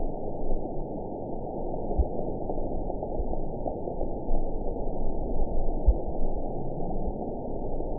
event 910288 date 01/17/22 time 23:52:44 GMT (3 years, 3 months ago) score 8.03 location TSS-AB01 detected by nrw target species NRW annotations +NRW Spectrogram: Frequency (kHz) vs. Time (s) audio not available .wav